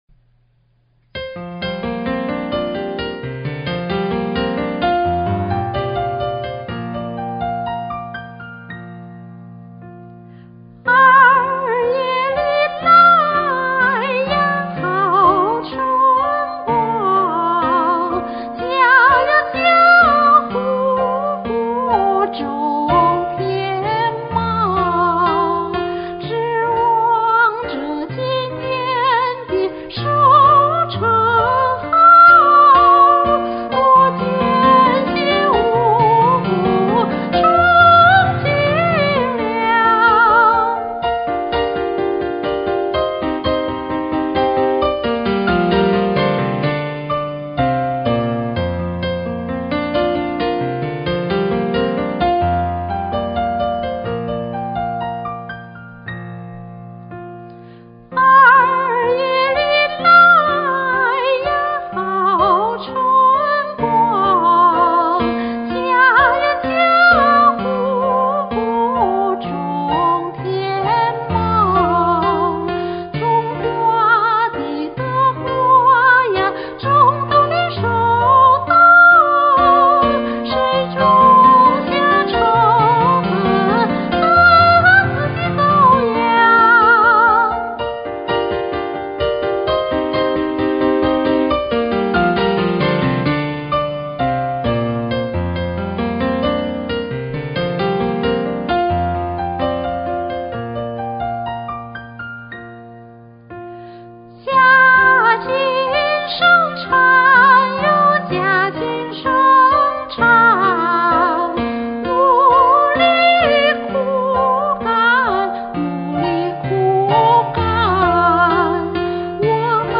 喜歡的一首老歌
這版是速度慢的，我還錄過速度快的。